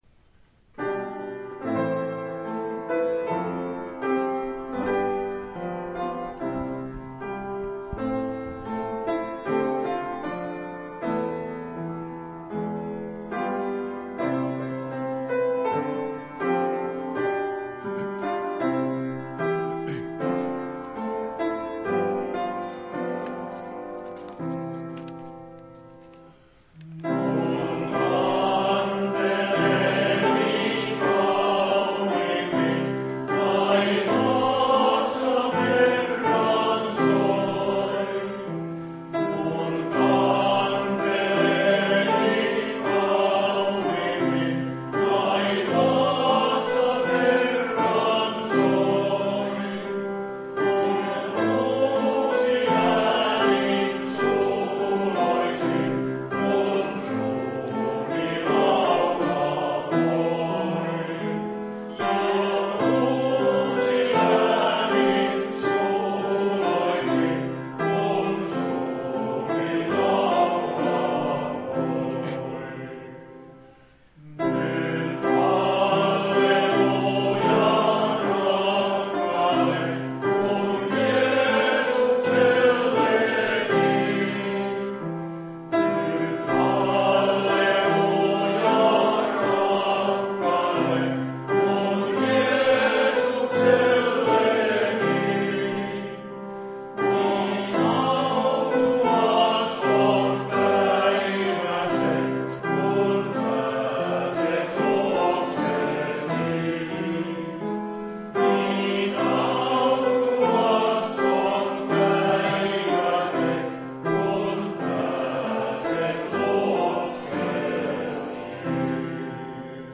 459 Teuvan srk-sali